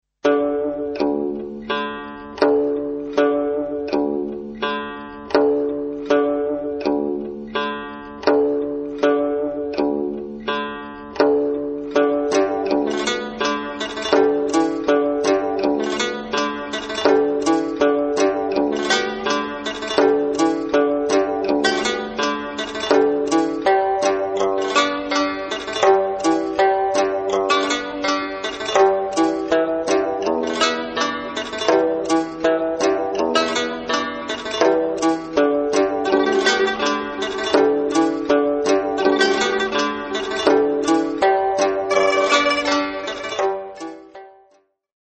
Guitar
relaxed slow instr.